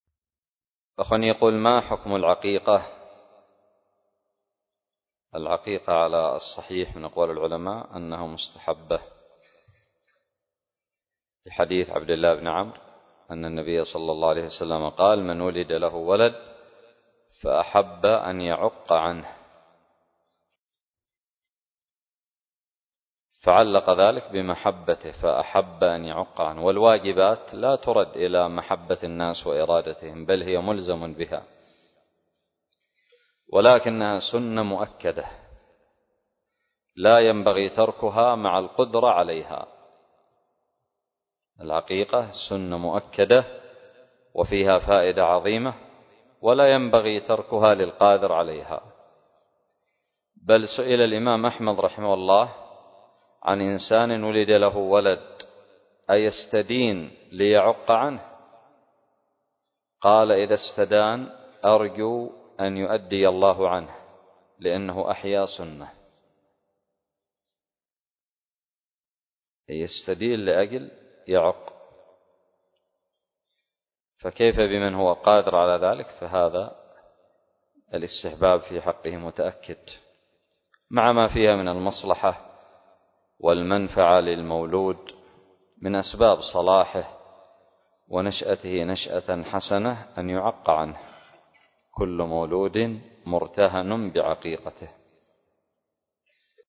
سؤال قدم لفضيلة الشيخ حفظه الله